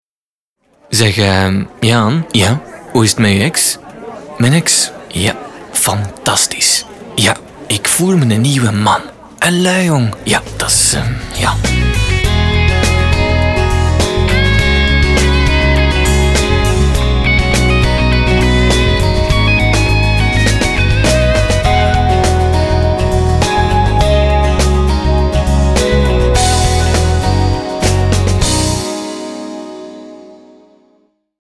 Sprecher flämisch
Sprechprobe: Industrie (Muttersprache):
flemish voice over artist